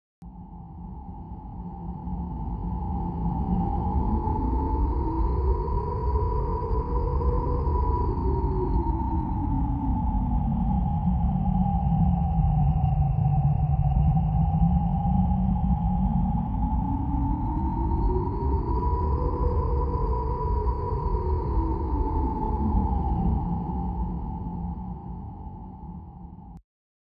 Download Free Howling Wind Sound Effects
Howling Wind